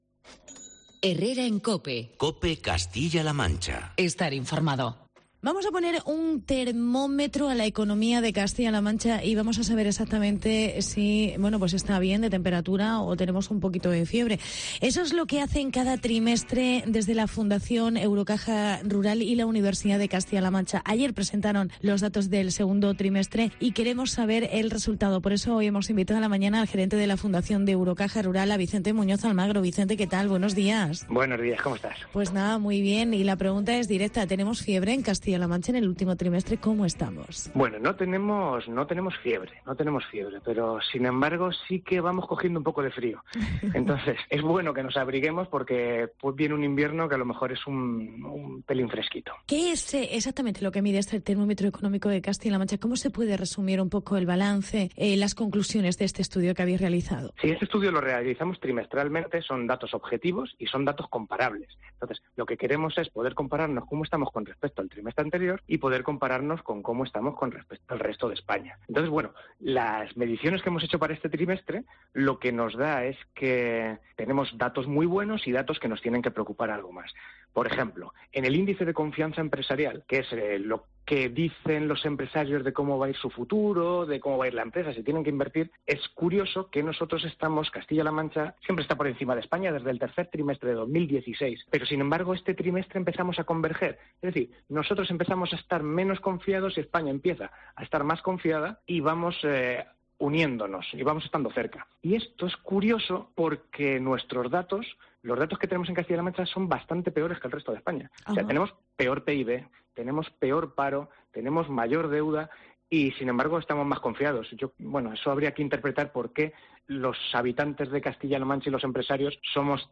Los empresarios confian en la evolución de la economía de CLM. Entrevista